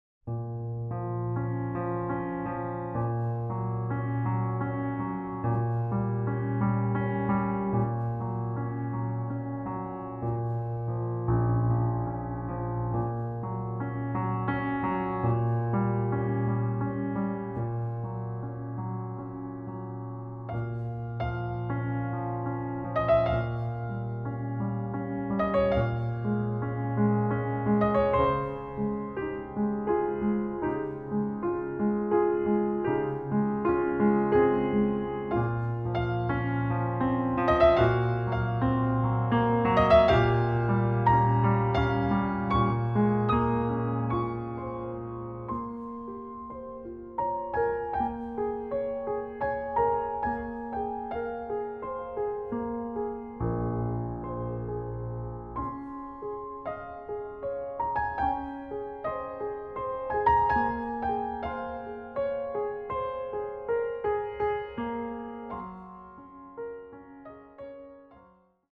into a classical-pop-jazz atmosphere.
these piano pieces become the soundtrack of a video